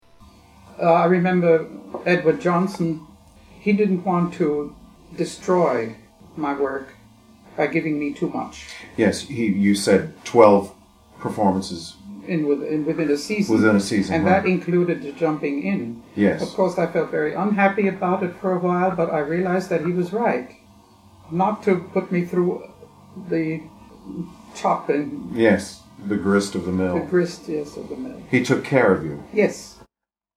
I have included some of her singing and also chose to include two narratives - her letter of introduction to the Met and the saga of saving a Met Götterdämmerung, which are read by me.